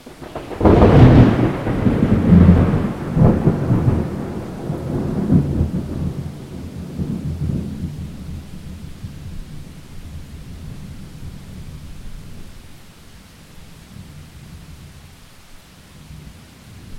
دانلود آهنگ رعد و برق 1 از افکت صوتی طبیعت و محیط
دانلود صدای رعد و برق 1 از ساعد نیوز با لینک مستقیم و کیفیت بالا
جلوه های صوتی